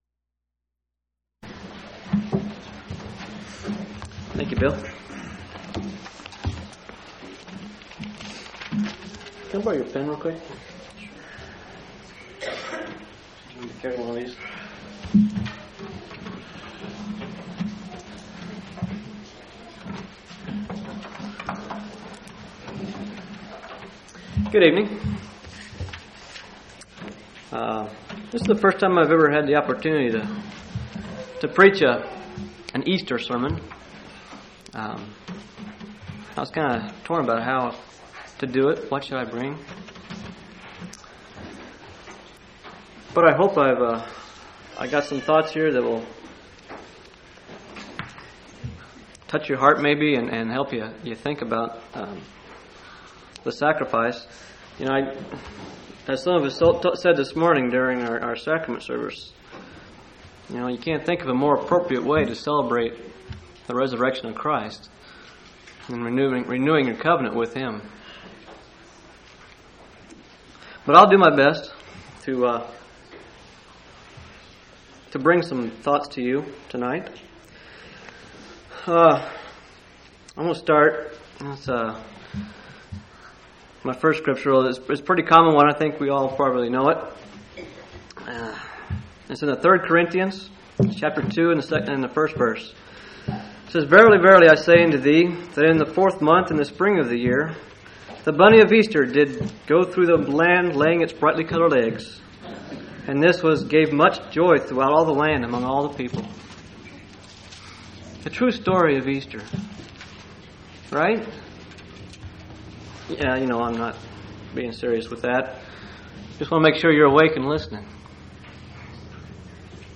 4/4/1999 Location: Phoenix Local Event